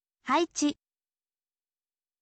haichi